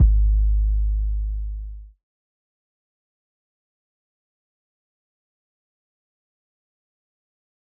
MB 808 (25).wav